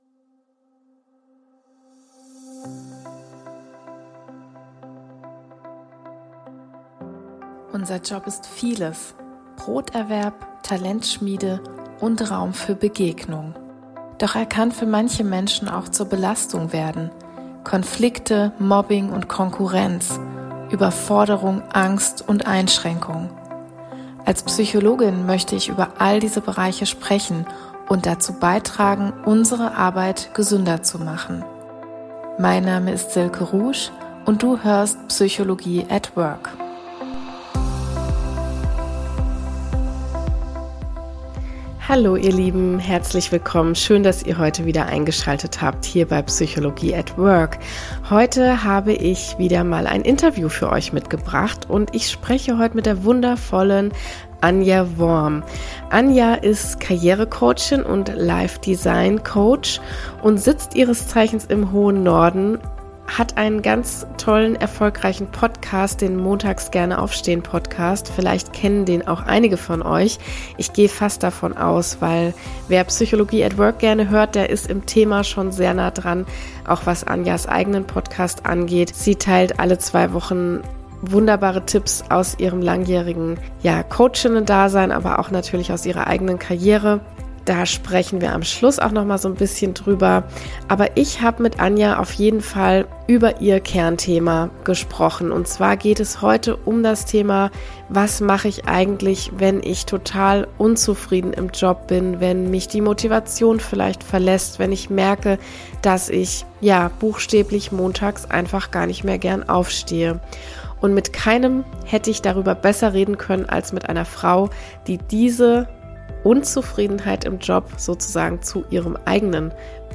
Beschreibung vor 5 Monaten Fühlst Du Dich im Beruf lustlos, uninspiriert, unzufrieden, leer? Wir alle kennen solche Phasen, und heute habe ich einen Interviewgast an meiner Seite, der diese Unzufriedenheit AUCH gut kennt - von ihren Klient:innen nämlich.